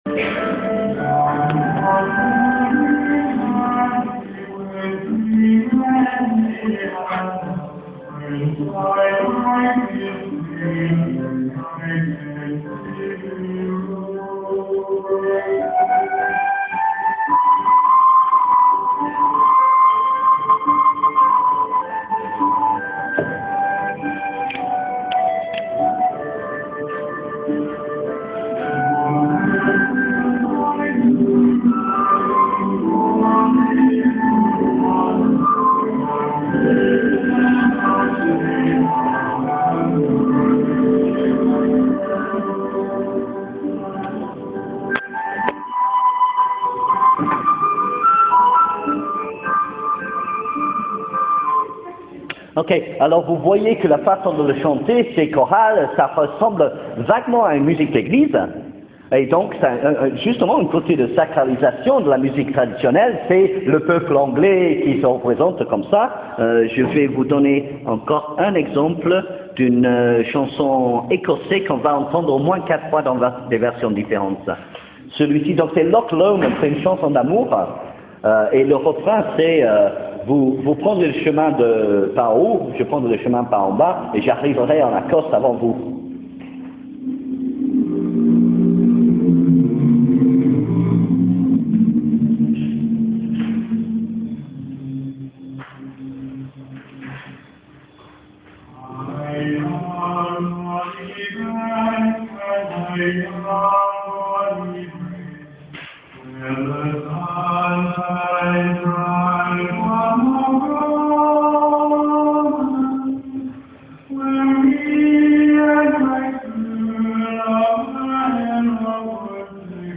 Pour vous aider à réviser, si vous êtes en régime général, ou pour vous permettre de préparer l'examen si vous êtes en régime dérogatoire, j'ai enregistré des extraits des cours de cette option.